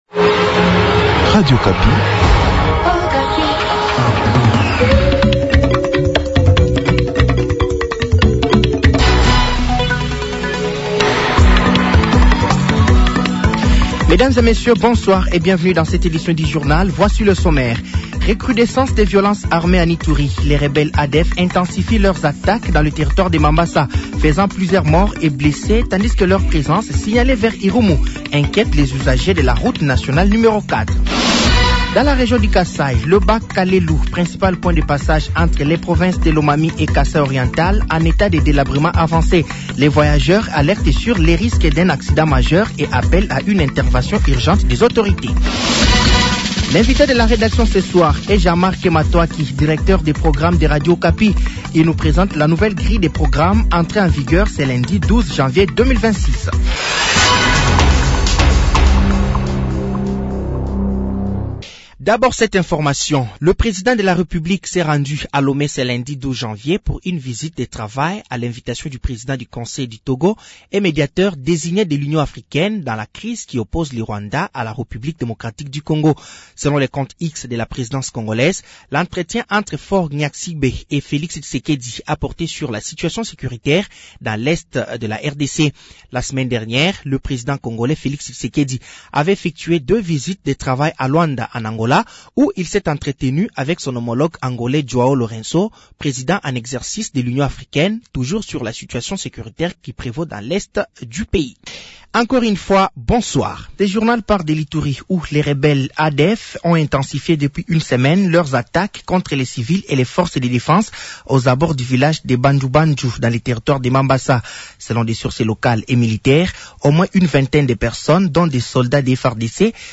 Journal français de 18h de ce lundi 12 janvier 2026